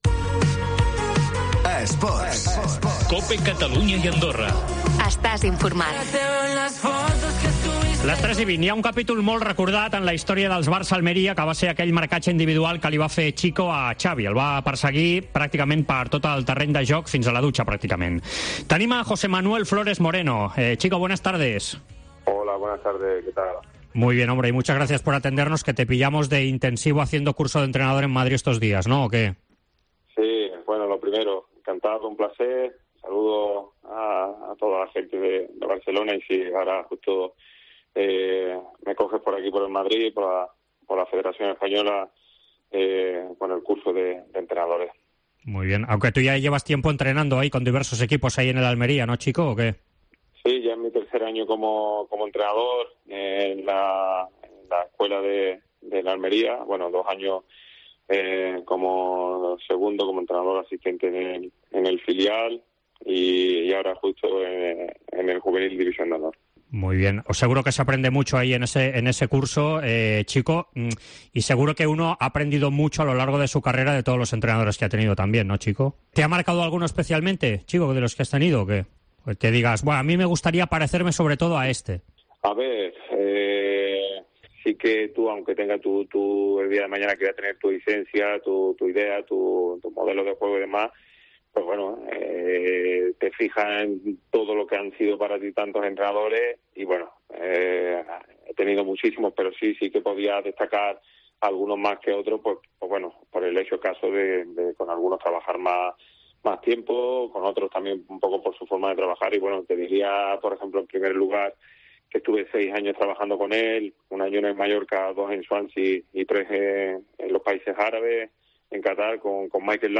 AUDIO: Parlem amb l'exjugador de la UD Almeria en la prèvia del partit a Montjuïc.